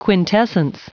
Prononciation du mot quintessence en anglais (fichier audio)
Prononciation du mot : quintessence